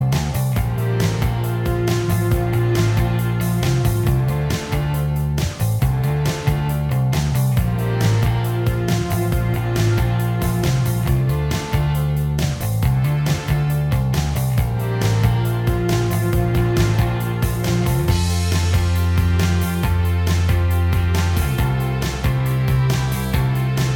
Minus Guitars Indie / Alternative 4:29 Buy £1.50